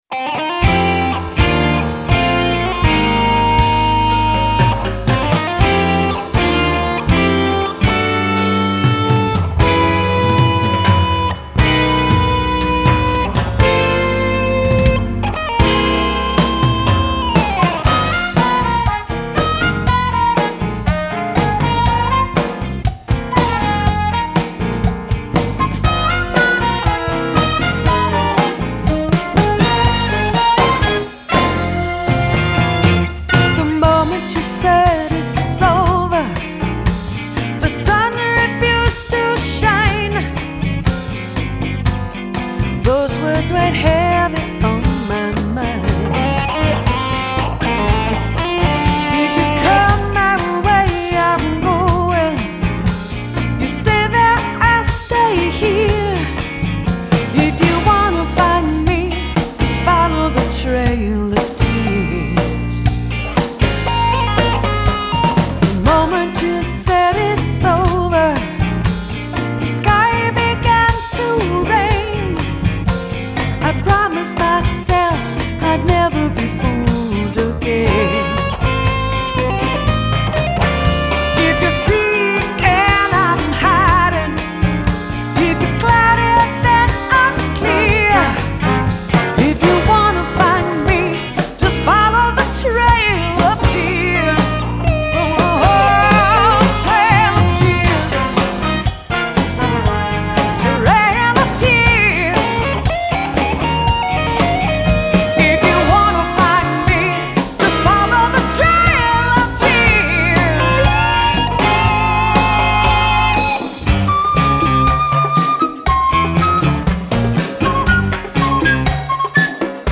Genre: Contemporary Jazz.